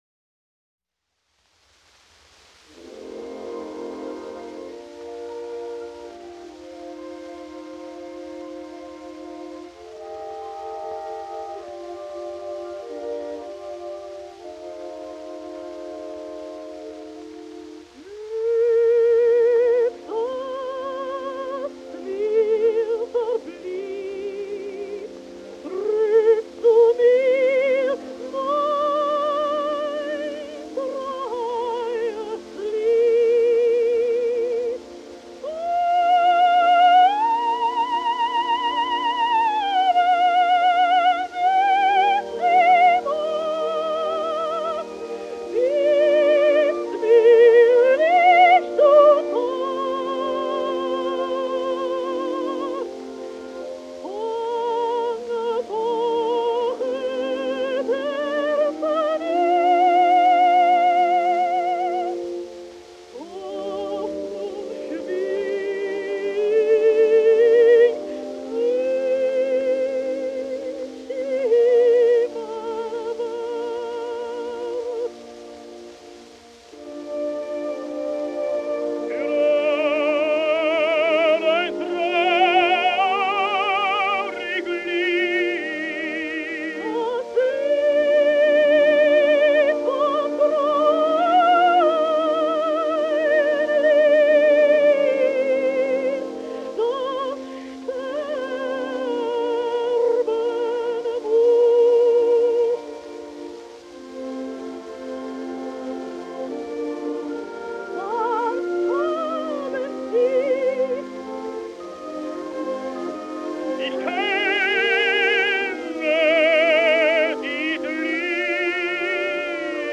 лирико-драм. сопрано